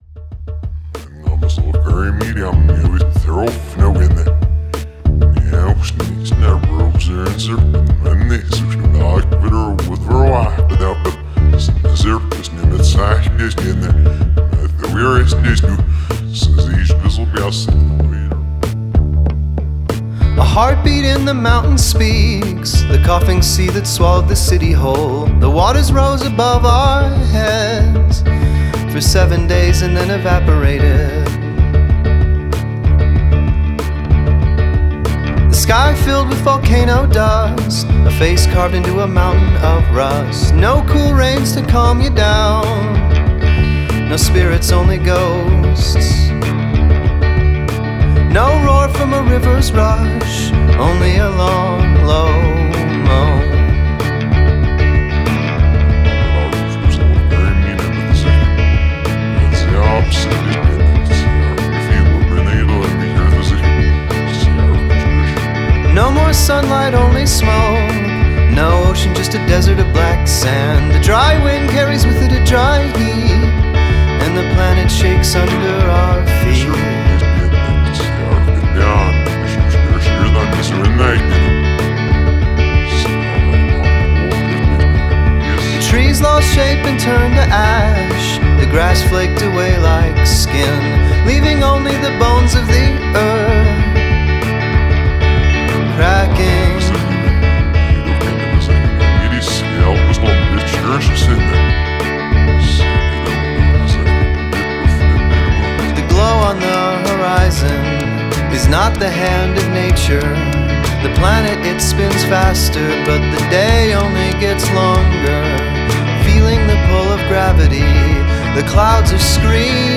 Must include prominent use of backwards recording
Fantastic vocals, and a pleasant enough melody.